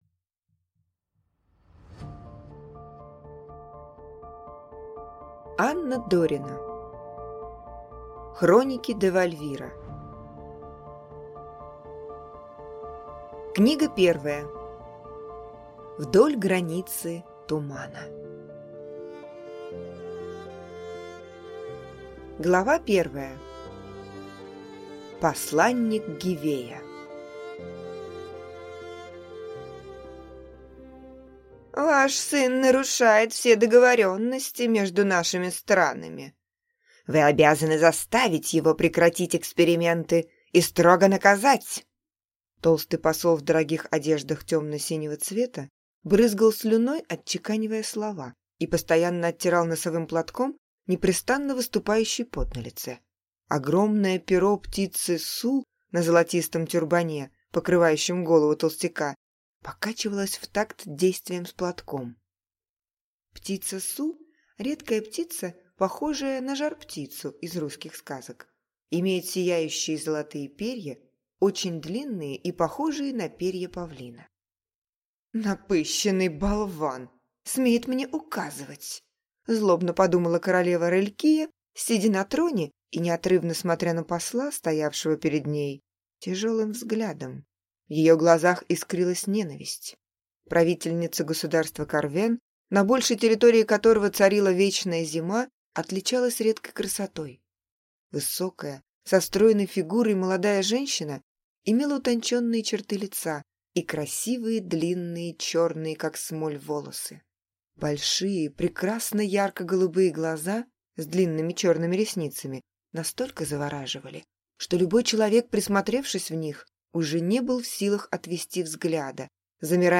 Аудиокнига Вдоль границы тумана | Библиотека аудиокниг